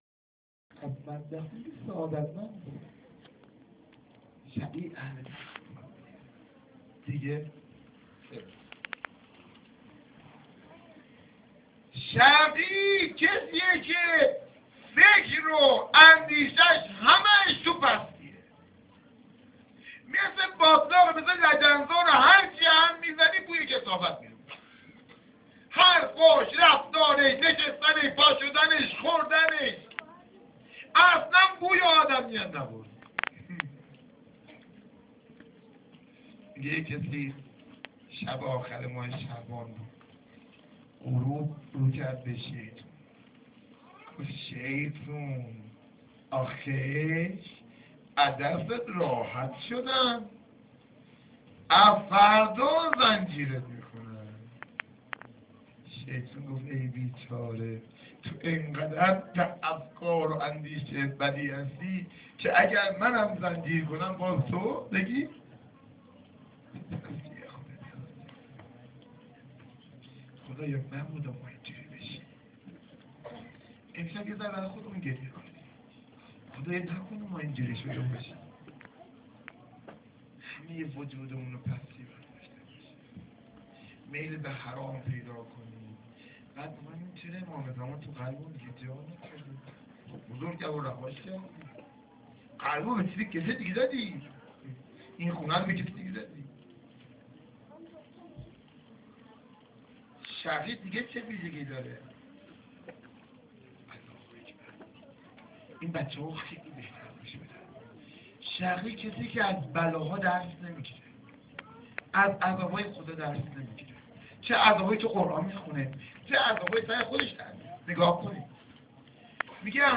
سخنرانی3.amr